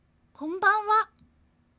ボイス
ダウンロード 女性_「こんばんわ」
大人女性挨拶